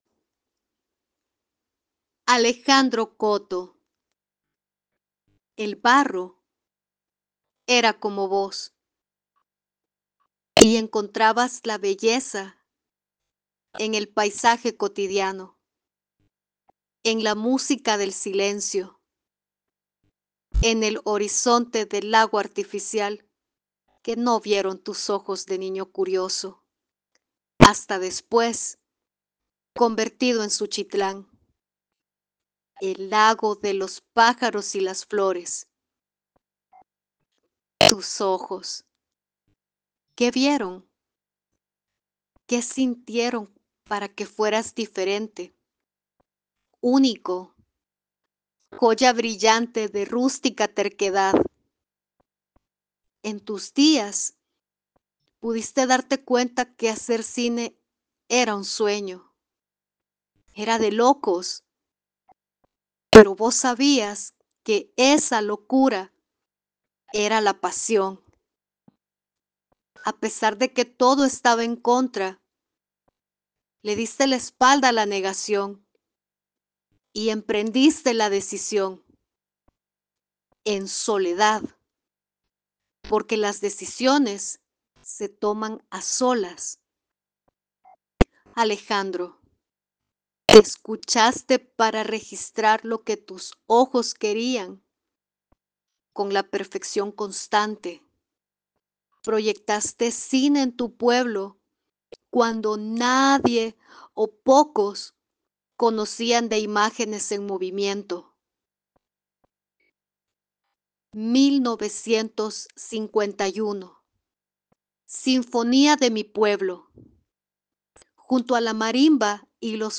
ensamble y fusión de teatro, música y danza
El performance incluyó la participación del público, quienes al final con velas en mano cantaron el himno y rindieron homenaje al creador del escudo de Suchitoto.
Performance Grupo de Teatro Complejo Educativo Los Almendros Suchitoto.